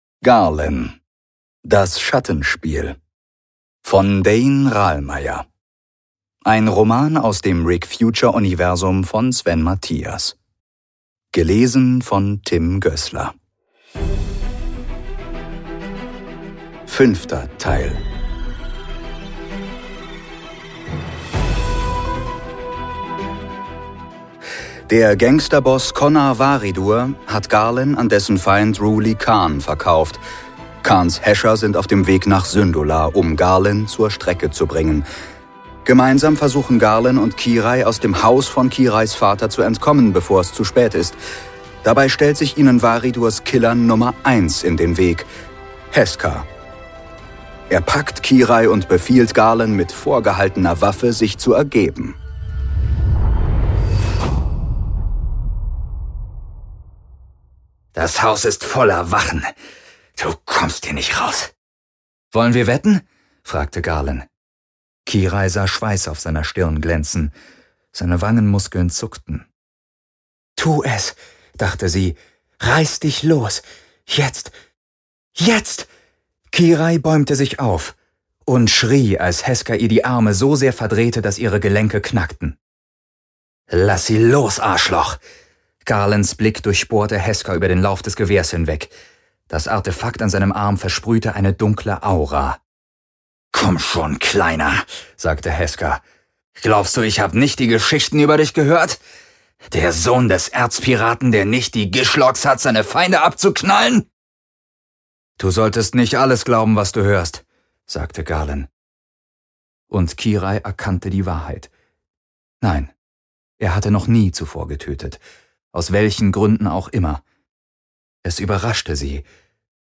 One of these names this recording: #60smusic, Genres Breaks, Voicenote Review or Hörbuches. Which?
Hörbuches